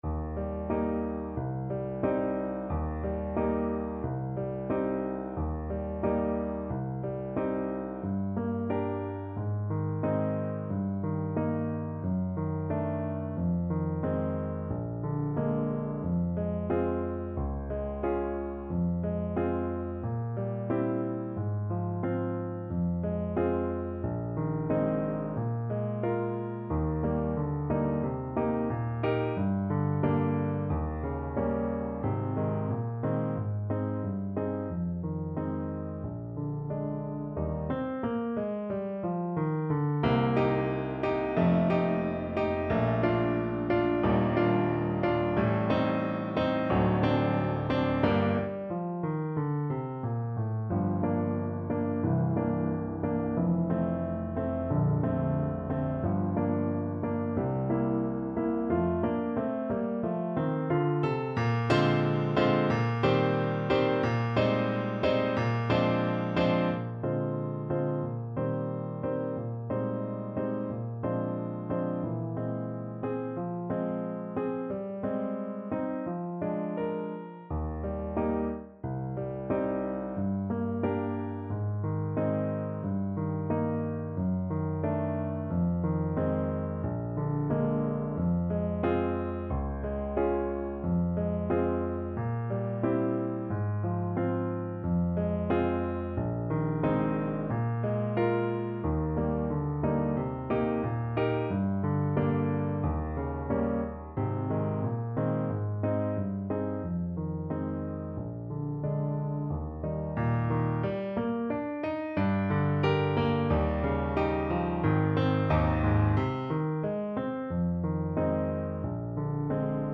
2/4 (View more 2/4 Music)
~ = 100 Allegretto con moto =90
Classical (View more Classical Trombone Music)